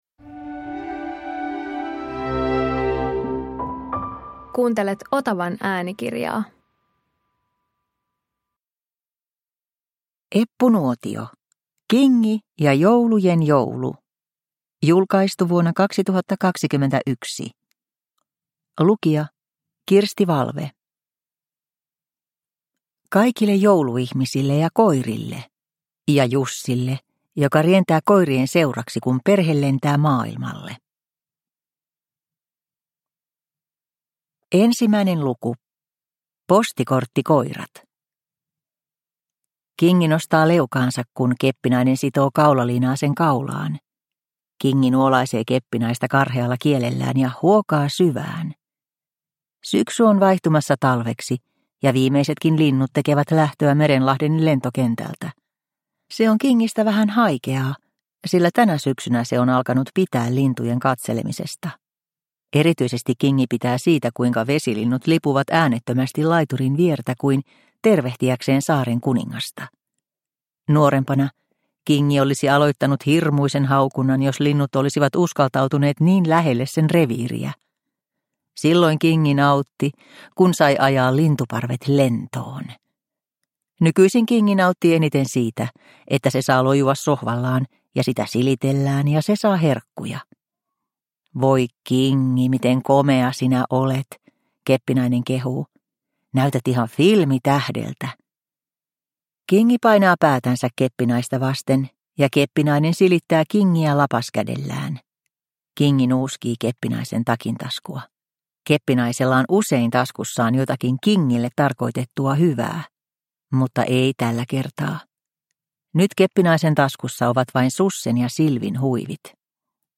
Kingi ja joulujen joulu – Ljudbok – Laddas ner